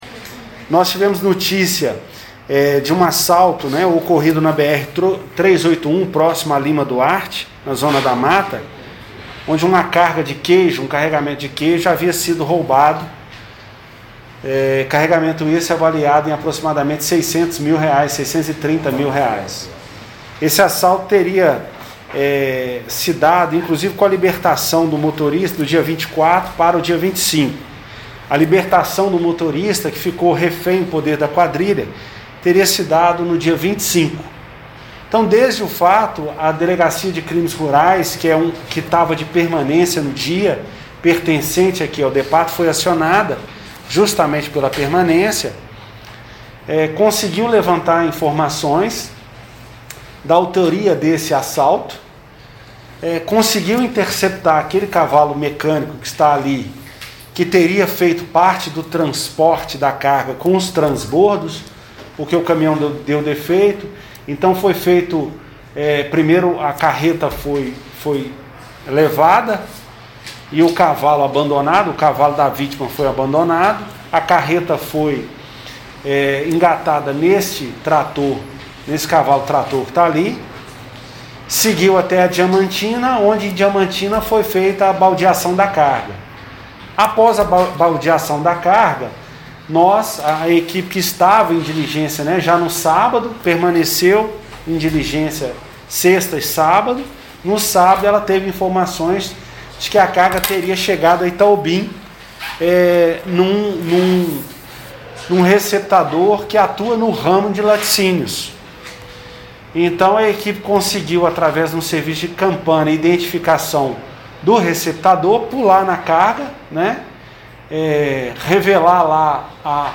Coletiva-1.mp3